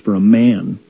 Type 1: Syllabic [ɹ] -- which could also be viewed as a rhotic or r-colored schwa -- followed by a non-rhotic reduced vowel, of the type that is usually transcribed with the symbol schwa (an upside-down letter 'e'). Note that the third formant (which is low in American English [ɹ]) is already low at the release of the [f], and then rises shortly before the [m]-closure, since the schwa is non-rhotic or at least less r-colored.